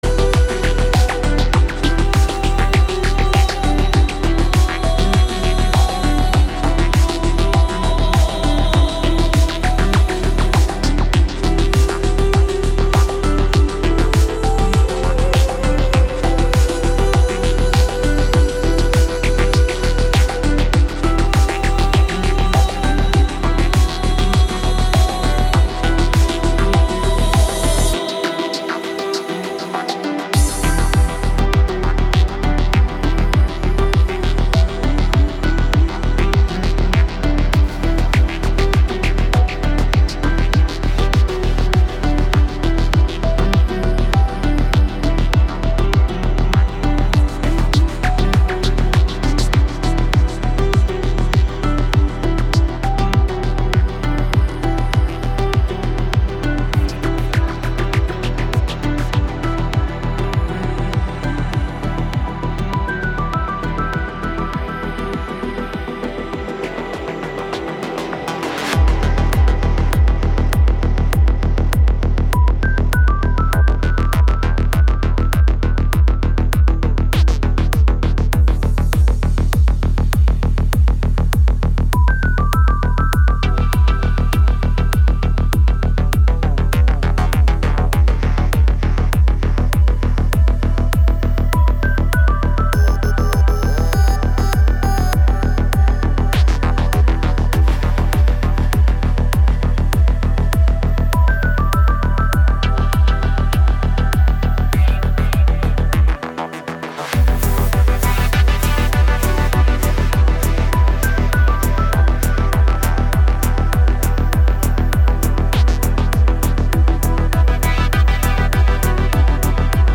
Жанр: Chill-Ambient